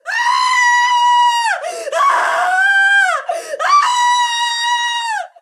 Mujer gritando asustada 2
gritar
miedo
mujer
Sonidos: Voz humana